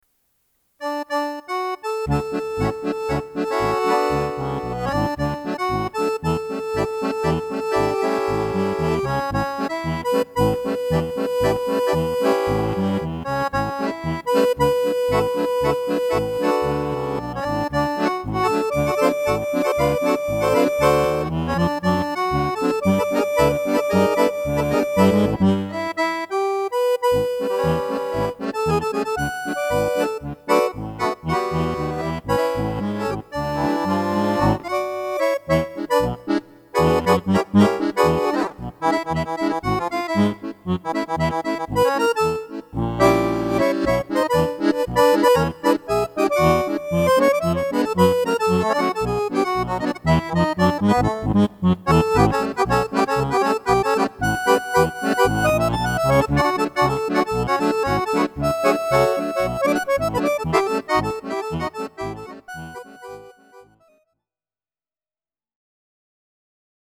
verm. tatsächlich vergessen, den Limiter abzuschalten